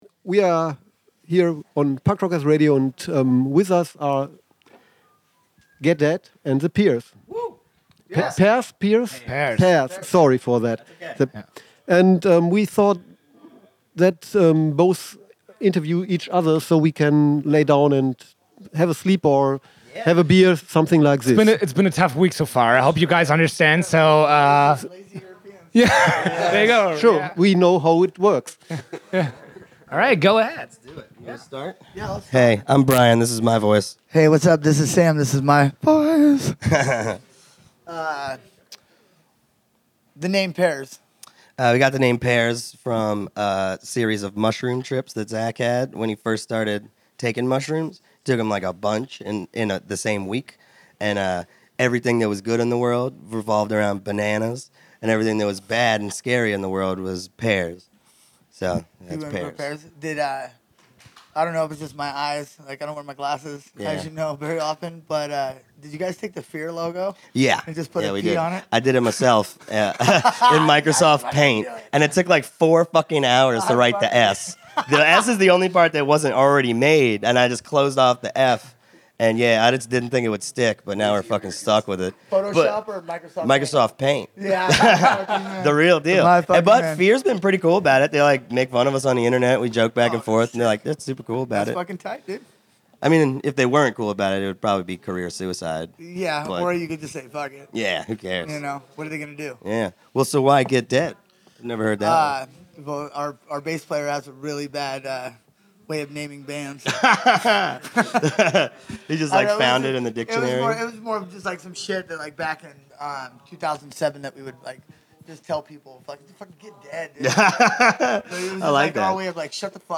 Aufgenommen beim Punk Rock Holiday 1.7. + WERBUNG + Hill´s Science Plan bietet leckere Vielfalt für jedes Tier.